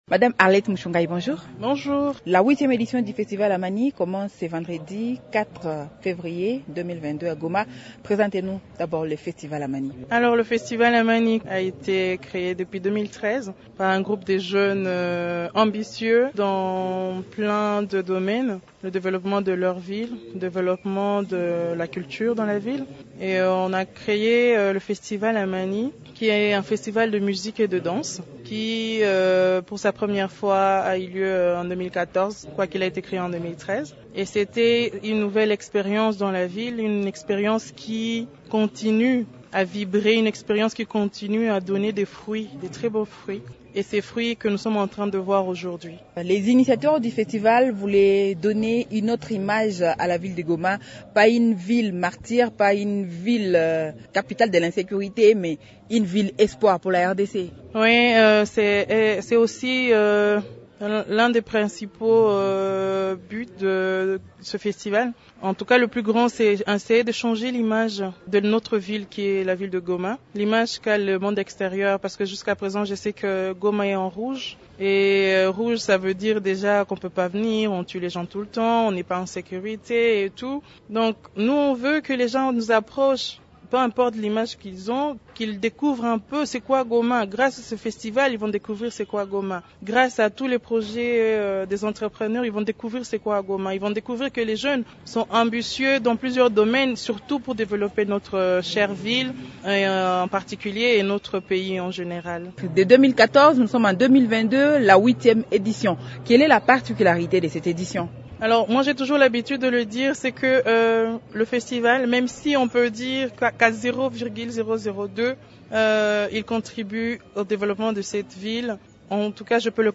s’entretient avec